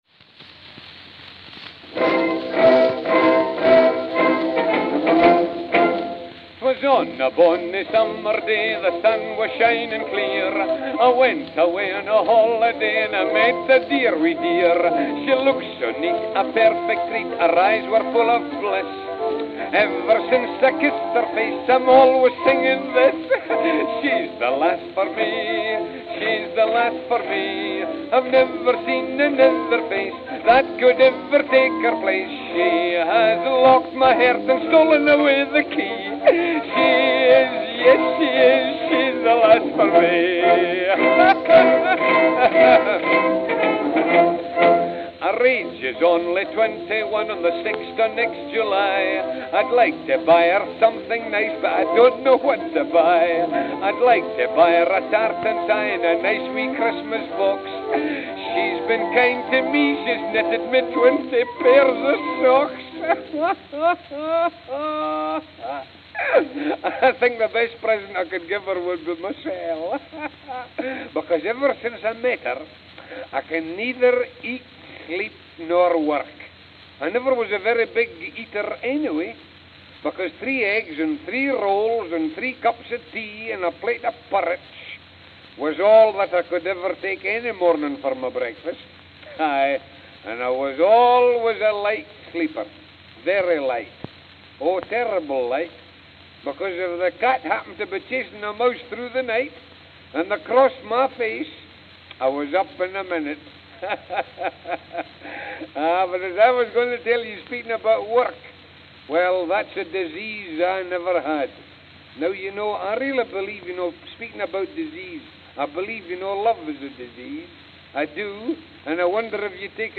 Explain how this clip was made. January 18, 1913 (Camden, New Jersey) (9/10)